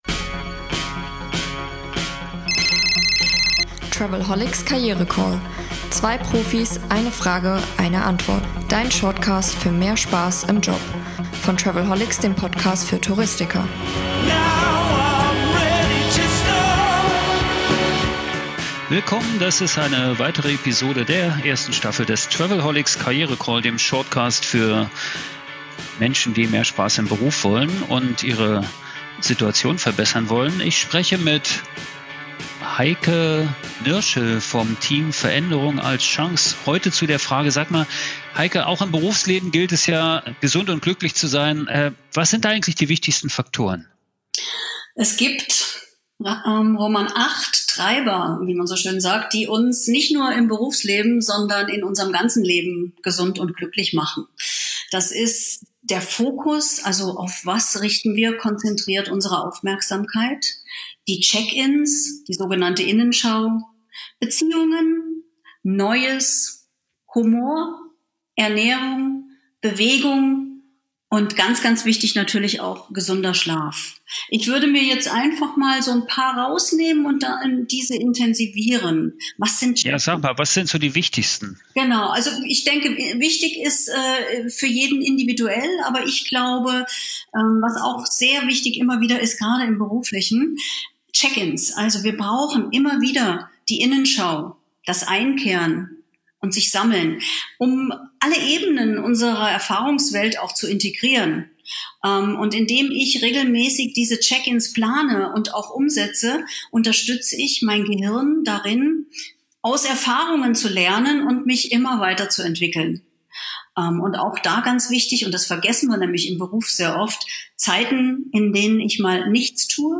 Der travelholics KarriereCALL Staffel 1: täglich - 2 Profis - 1 Frage - 1 Mic
In den kurzen Bonus Episoden des Podcast für Touristiker spreche ich mit den Coaches vom Team Veränderung als Chance über berufliche Neuorientierung und ganz praktische Tipps für mehr Freude und Erfolg im Job.